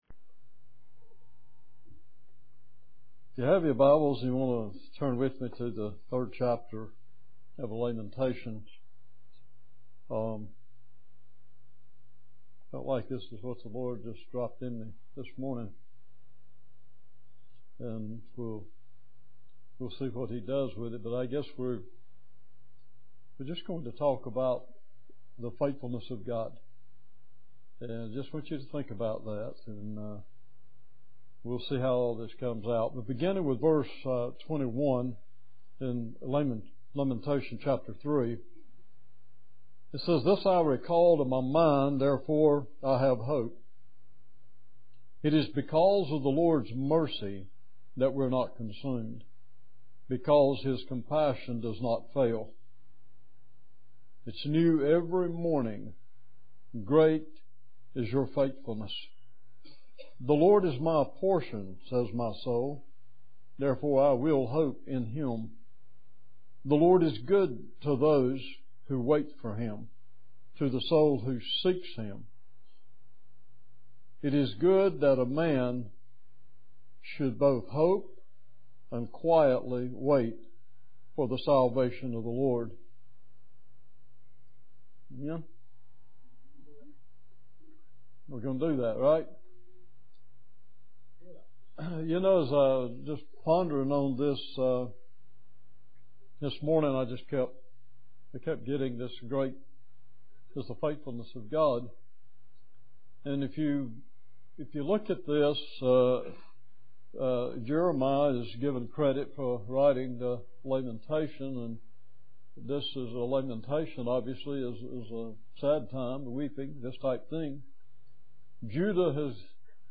Sermons on Lamentations